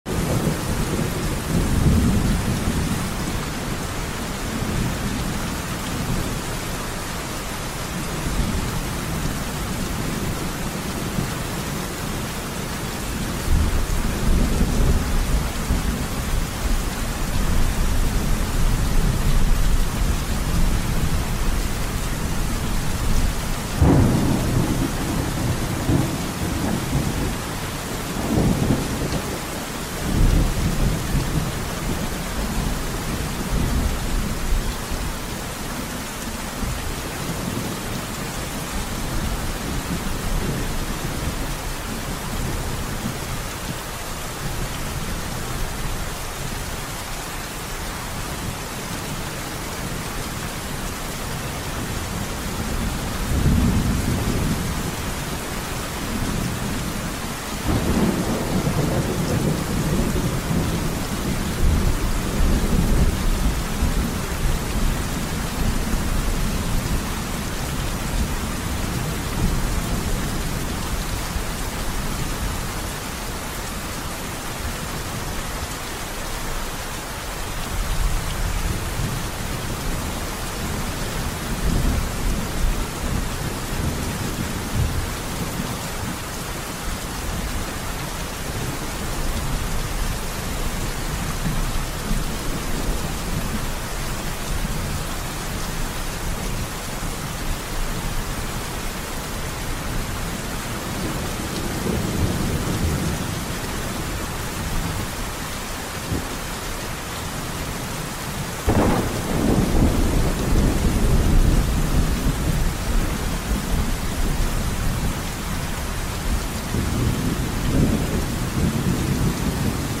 Unwind under the steady rhythm of falling rain as it taps gently on the metal roof of a hidden cabin in the woods. Let this peaceful soundscape clear your thoughts, soothe your senses, and guide you into deep relaxation or restful sleep.